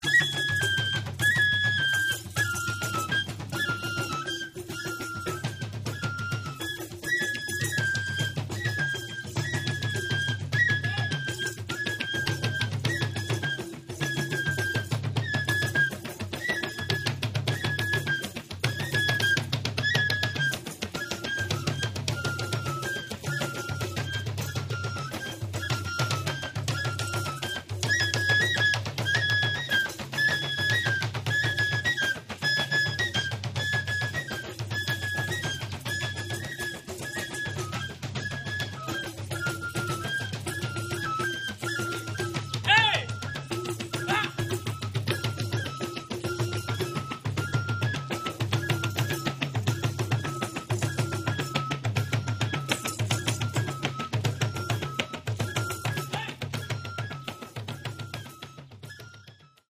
The kori is a closed pot-shaped gourd drum, the membrane of which is stretched over the opening of a gourd and tightened by means of ropes and pegs.
The korbala drum has a diameter of 50 cm and a depth of 30 cm and has a high clear tone. The kornia drum has a diameter of 70 cm and a depth of 50 cm and has a low but still fairly clear tone.
The kori drum is played with the hands, the palm of the hand and the fingertips.